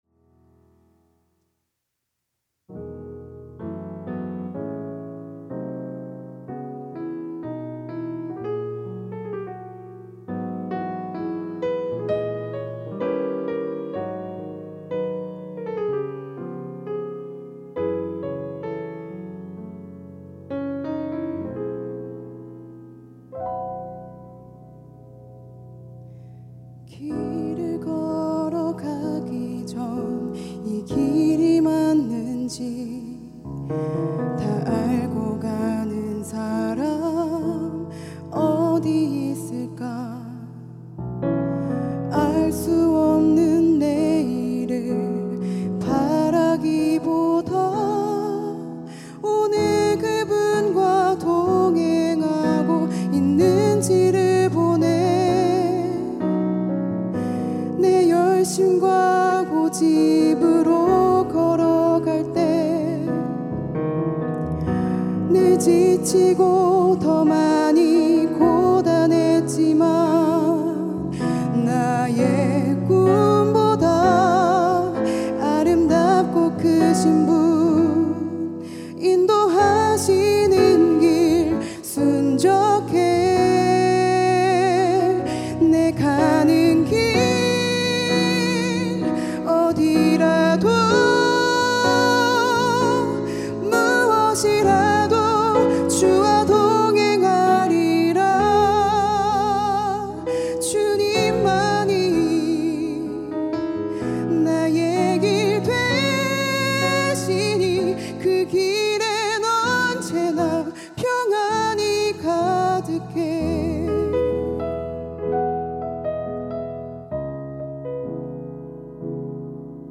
특송과 특주 - 내 가는 길